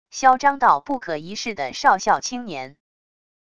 嚣张到不可一世的少校青年wav音频